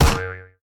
snd_bouncy.ogg